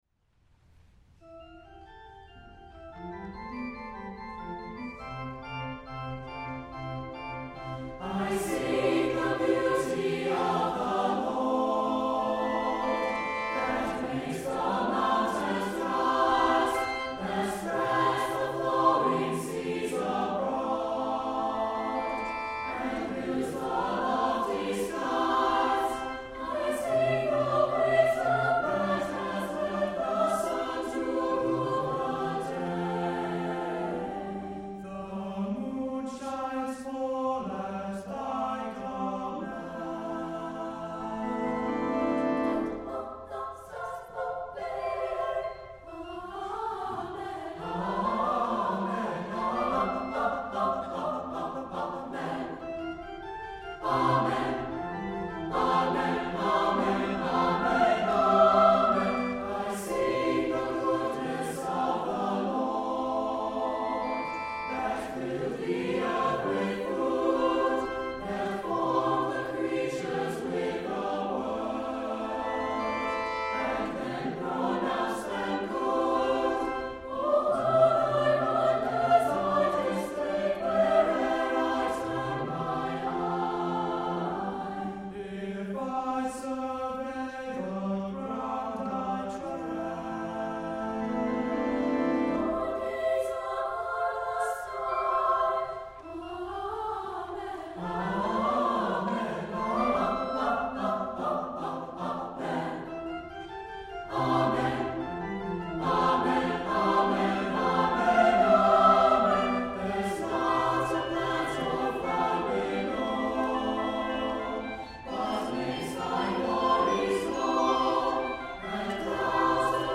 for SATB Choir and Organ (2003)